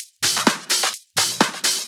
Index of /VEE/VEE Electro Loops 128 BPM
VEE Electro Loop 313.wav